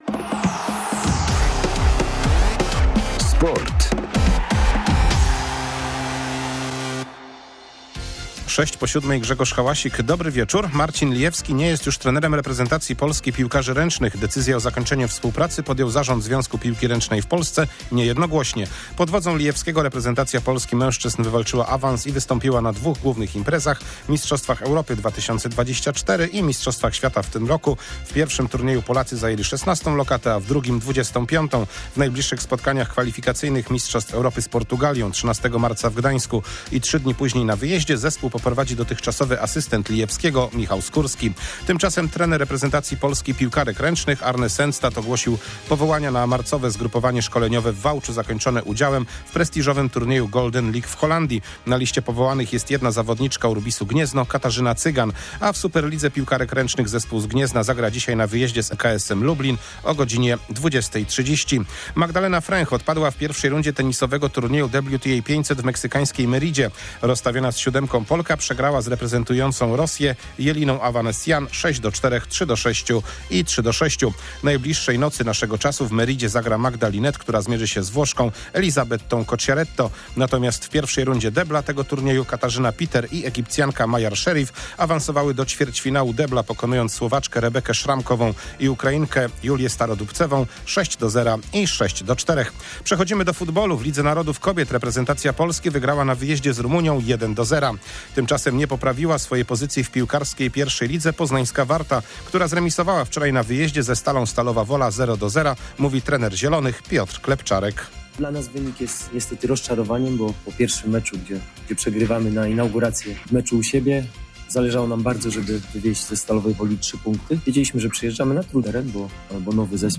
25.02.2025 SERWIS SPORTOWY GODZ. 19:05